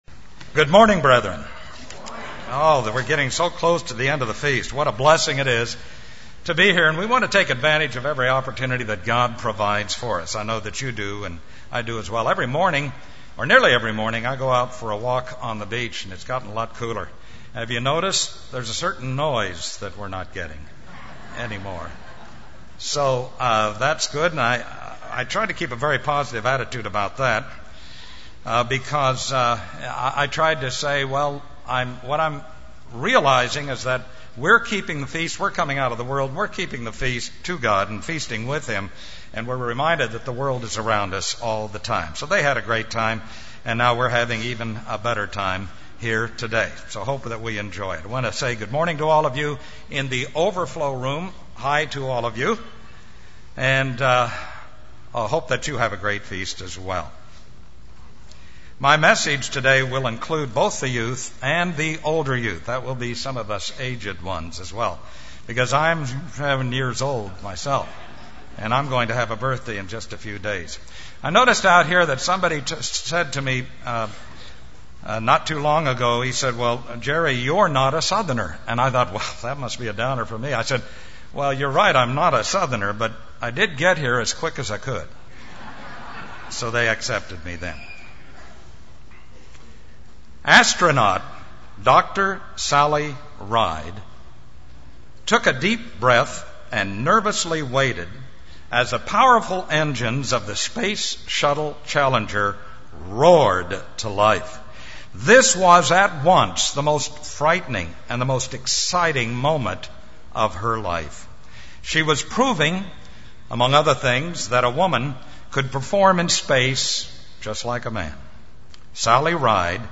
This sermon was given at the Panama City Beach, Florida 2007 Feast site.